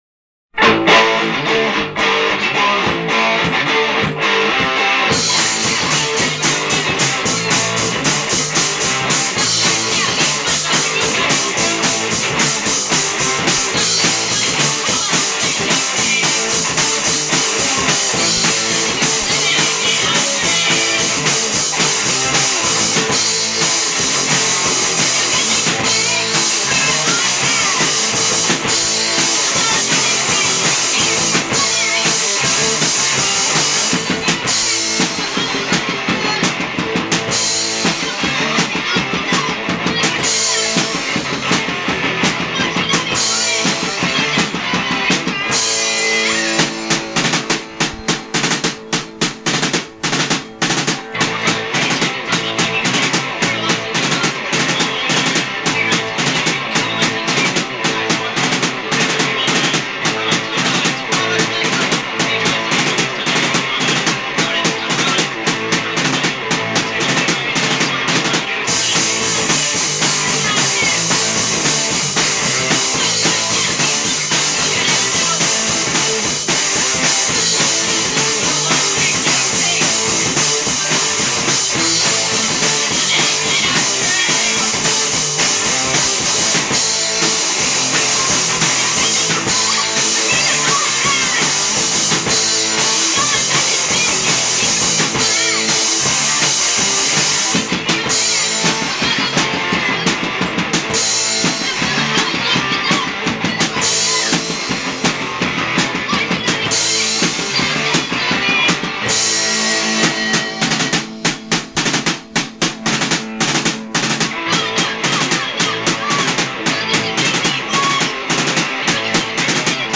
vocals
guitar
bass
drums.
These songs are from a practice tape.